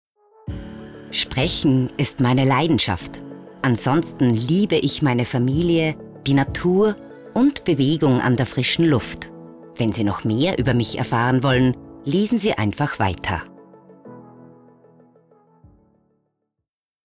Stimmfarbe: markant, seriös, tief, wandlungsfähig
Stimmalter: 40+